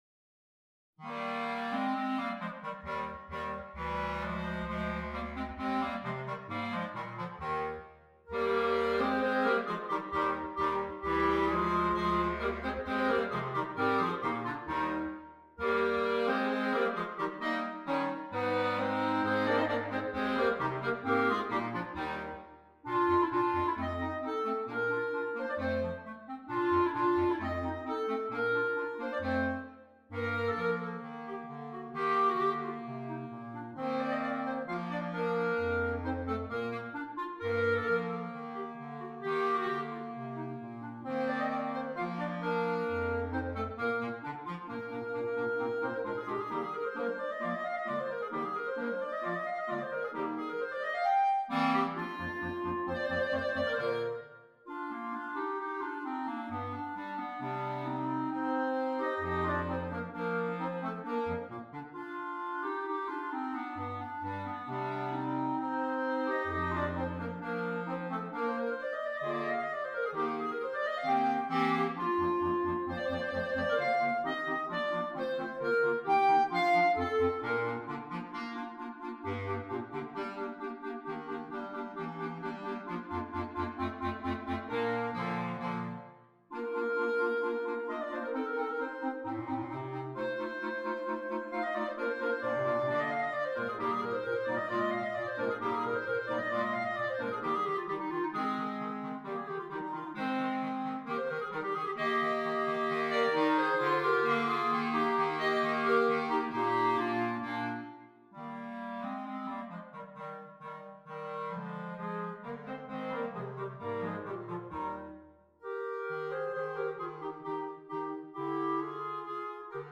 4 Clarinets, Bass Clarinet
clarinet choir